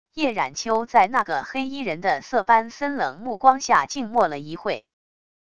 叶染秋在那个黑衣人的色斑森冷目光下静默了一会wav音频生成系统WAV Audio Player